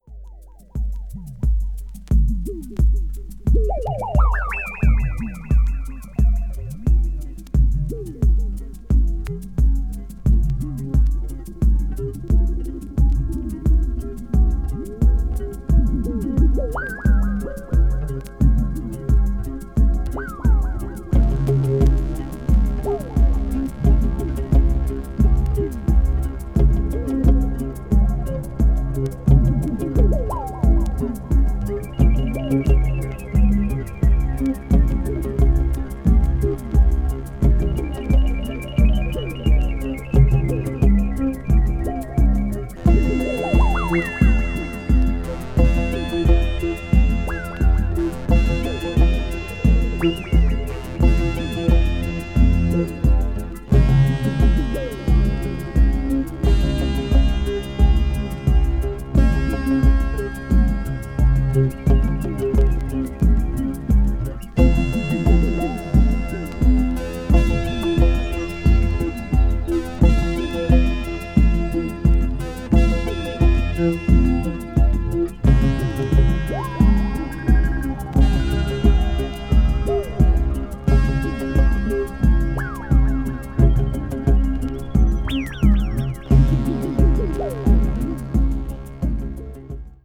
electronic   meditation   new age   oriental   synthesizer